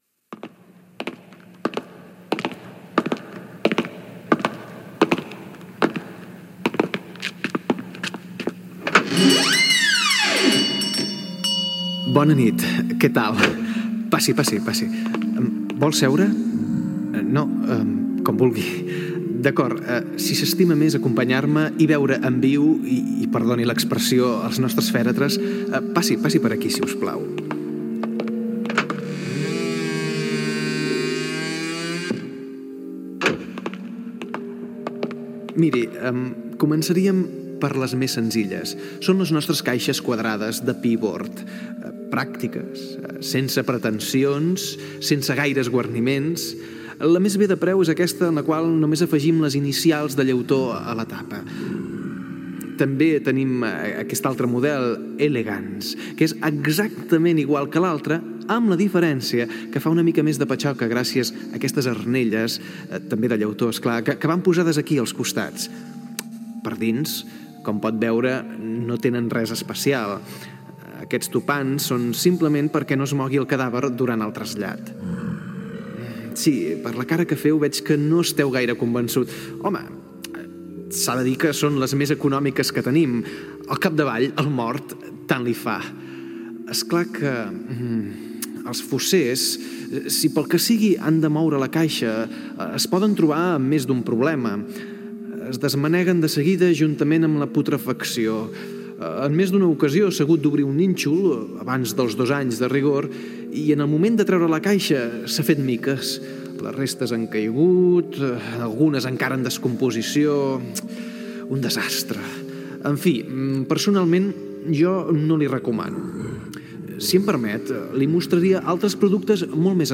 Un venedor de caixes de mort n'explica les característiques d'alguns models.
Entreteniment